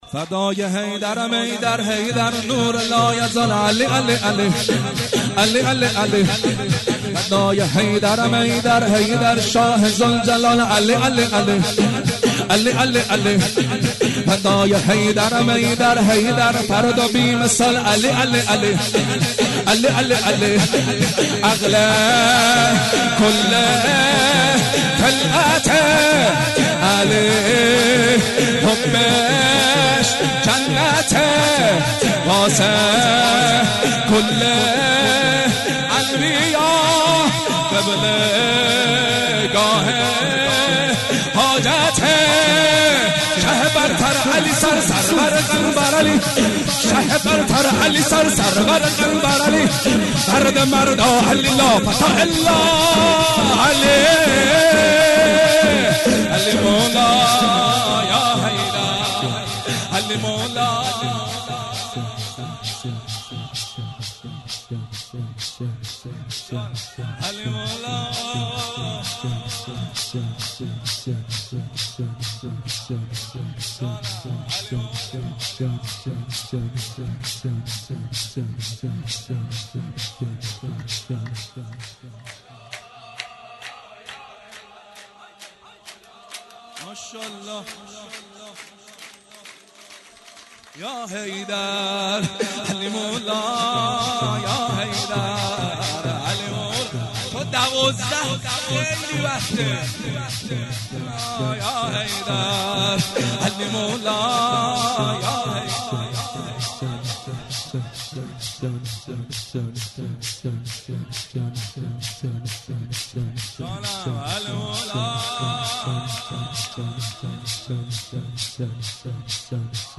جشن ولادت امام رضا ع ـ ۵مرداد ۹۷